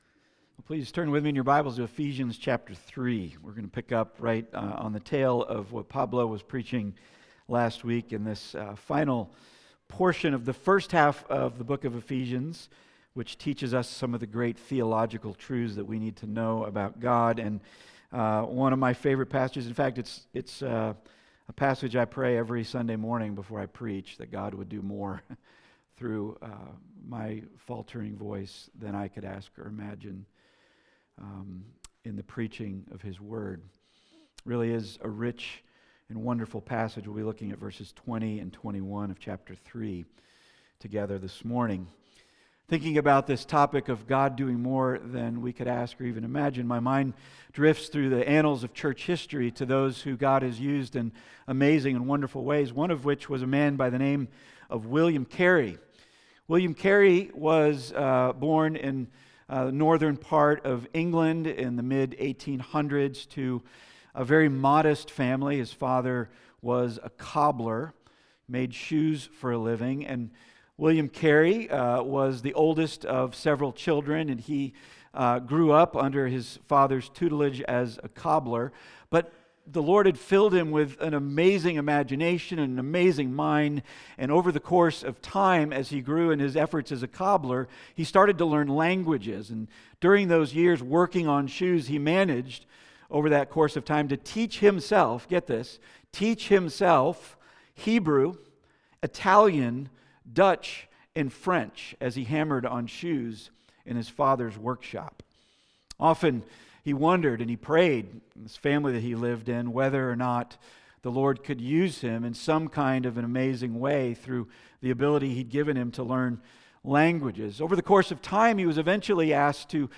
Passage: Ephesians 3:20-21 Service Type: Weekly Sunday